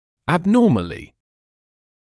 [əb’nɔːməlɪ][эб’ноːмэли]анормально; атипично; патологически